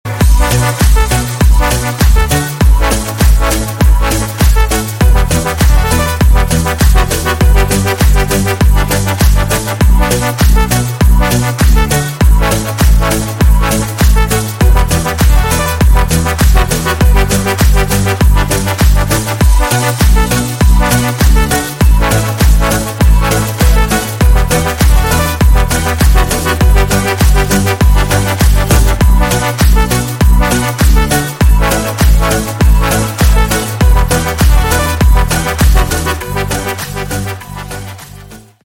Клубные Рингтоны » # Рингтоны Без Слов
Рингтоны Ремиксы » # Танцевальные Рингтоны